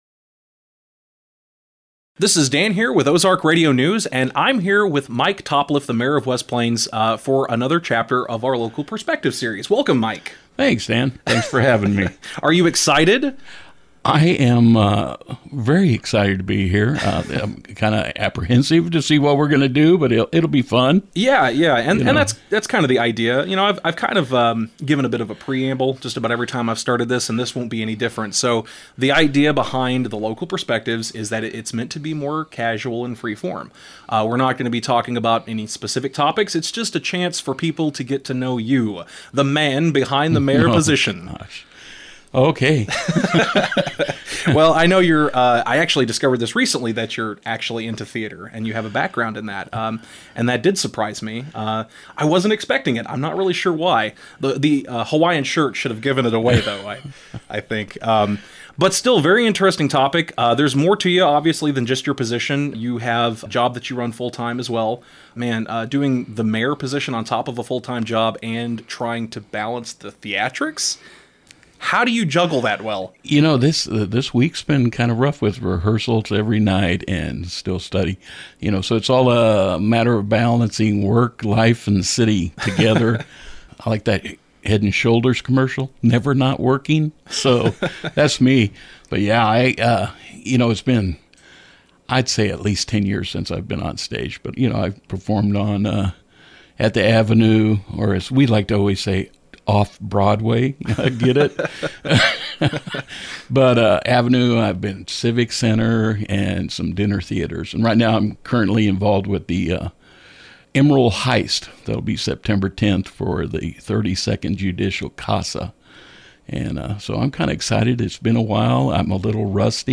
In this chapter of Local Perspectives, we spoke to the Mayor of West Plains, Mike Topliff. Conversation topics included his experiences in theater, the difficulties of his position and inflation, and even a sneak peek into his plans for Christmas later this year.